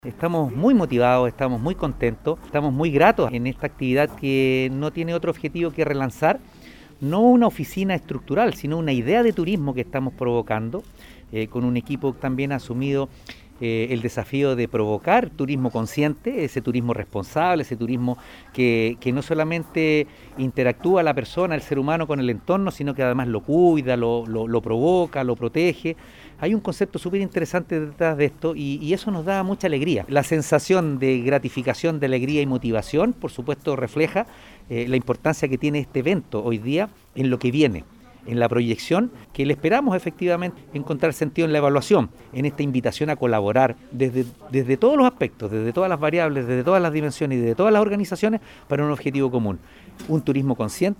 La actividad tuvo lugar en el local de San Pedro de Putupur, Restaurant Promo 385, y contó con la presencia de diferentes autoridades del turismo regional
Alcalde-Oscar-Calderon-Sanchez.mp3